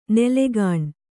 ♪ nelegāṇ